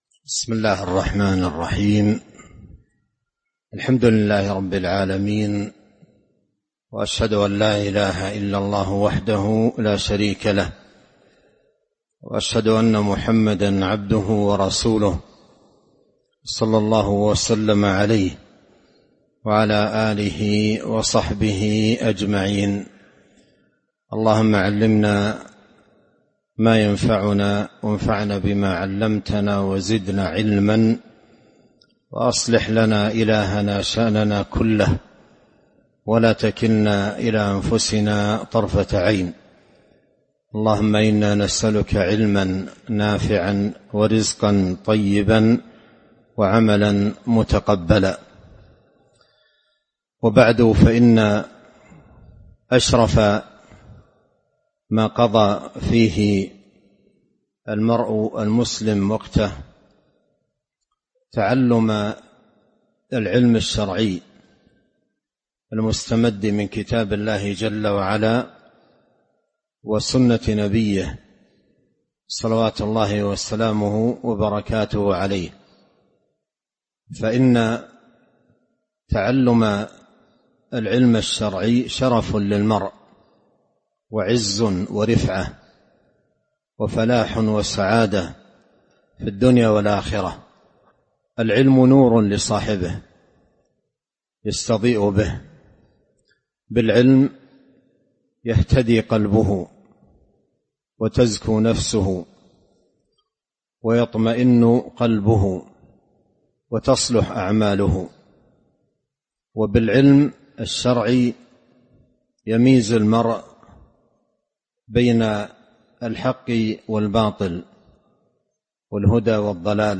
تاريخ النشر ٥ ربيع الأول ١٤٤٦ هـ المكان: المسجد النبوي الشيخ: فضيلة الشيخ عبد الرزاق بن عبد المحسن البدر فضيلة الشيخ عبد الرزاق بن عبد المحسن البدر فضائل طلب العلم